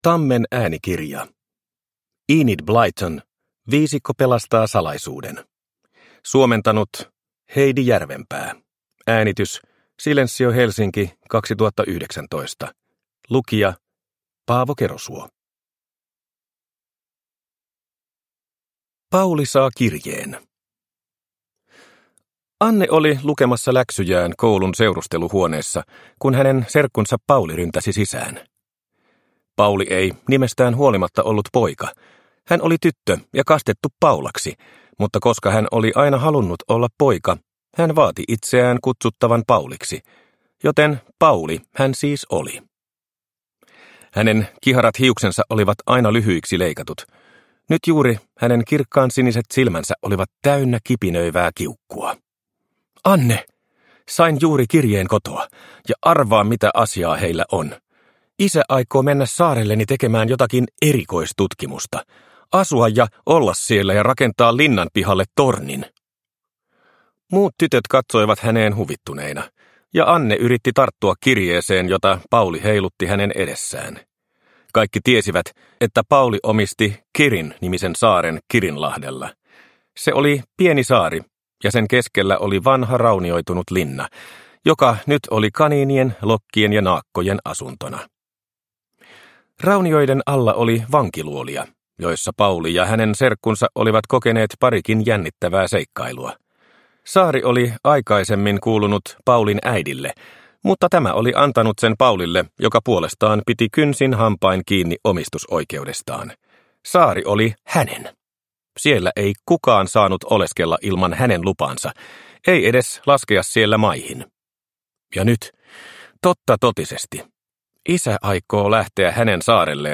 Viisikko pelastaa salaisuuden – Ljudbok